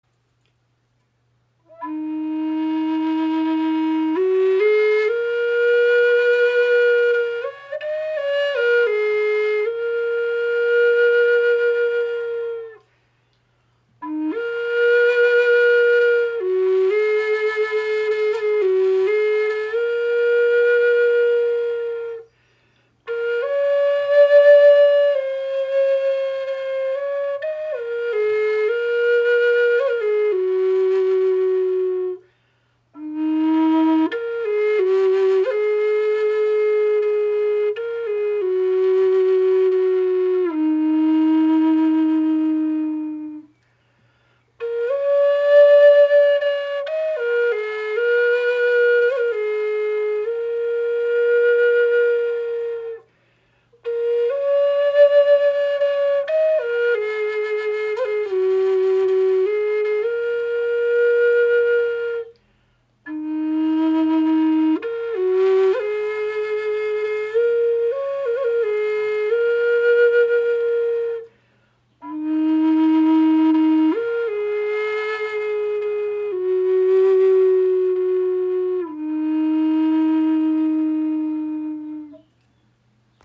Die Spirit Flute EarthTone in E-Moll (432 Hz) ist eine kompakte Bassflöte mit warmer, tiefer Klangfarbe.
Alle Spirit Flöten dieser Serie sind auf 432 Hz gestimmt.